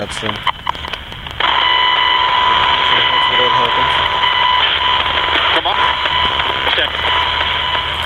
GhostBox #18 -  As I am standing near the 2 little stairs where my equipment is resting, there is a voice at the 5 second mark that says,  "Come up......check".   I did not catch this at the time,  my guess is that I am being asked to go up to the Mausoleum and check it out?